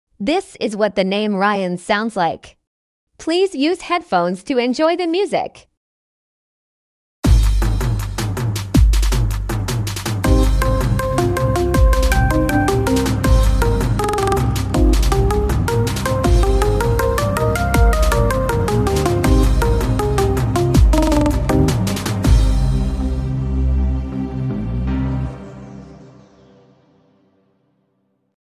midi art